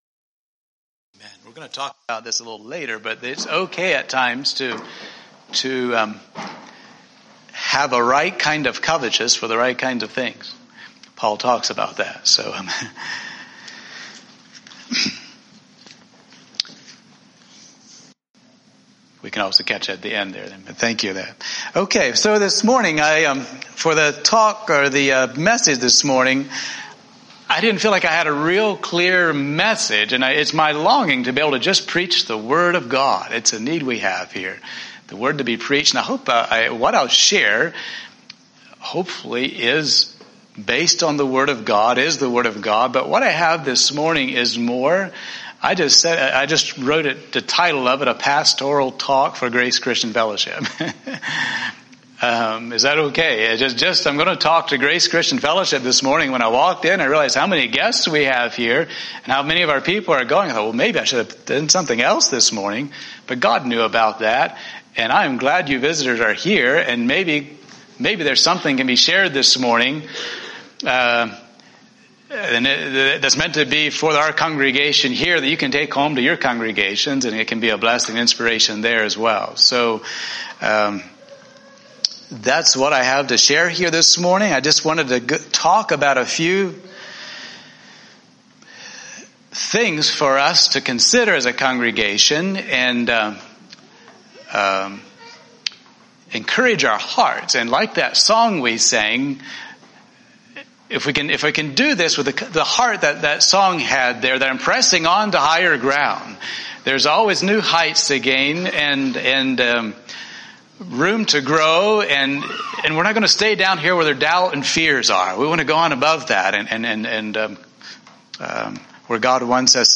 Pastoral Talk for G.C.F.
Pastoral-Talk-For-G.C.F.mp3